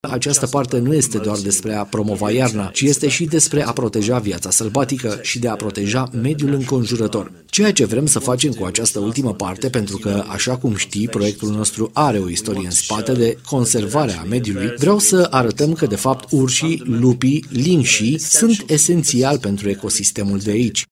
Acesta a venit în studio-ul Radio Romania Brașov FM, cel mai nou post de radio din rețeaua Radio România, care-și va începe emisia din 1 Martie 2019.